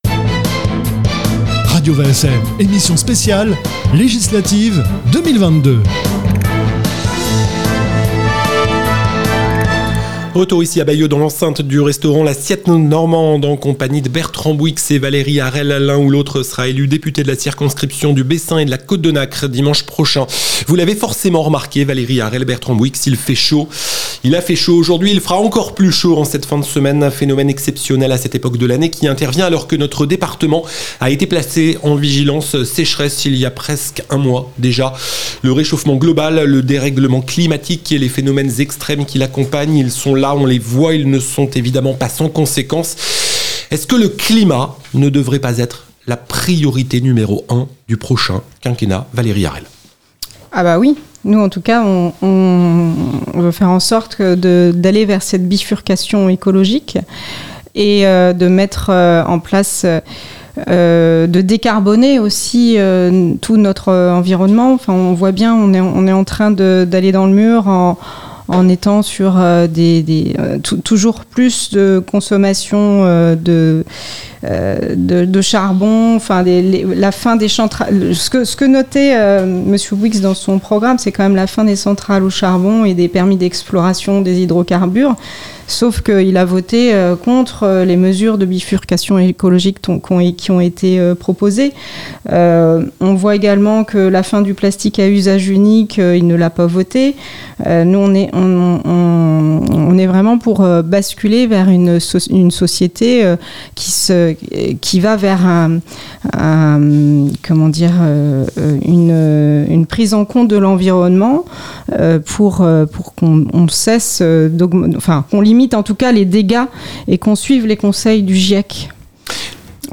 Débat Législatives 2022-5ème Circonscription